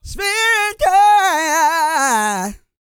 E-GOSPEL 119.wav